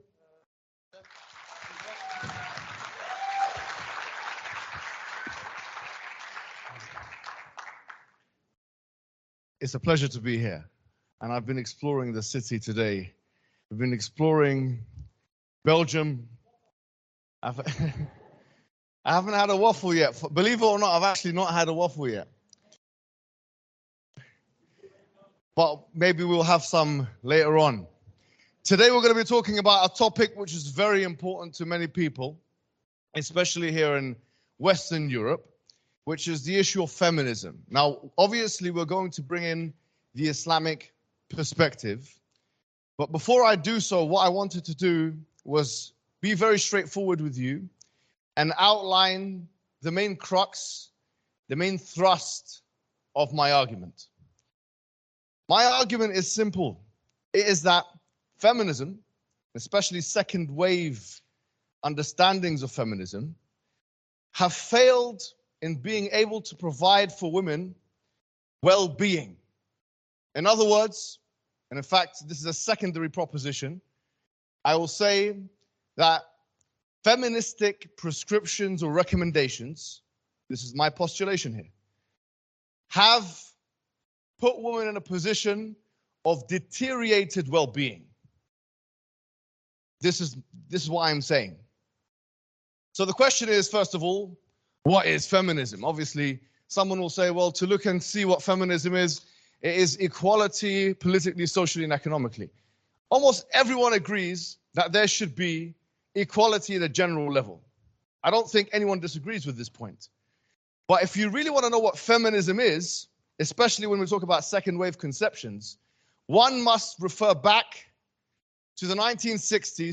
Sapience Institute/How Feminism has Harmed Women - Belgium Lecture